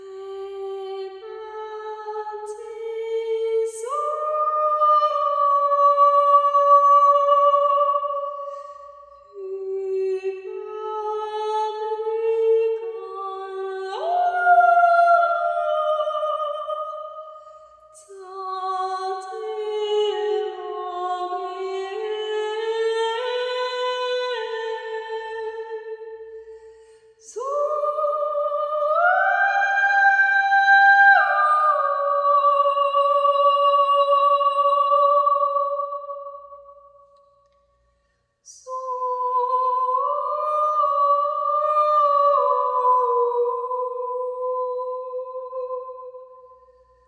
A Capella                    Durée 05:40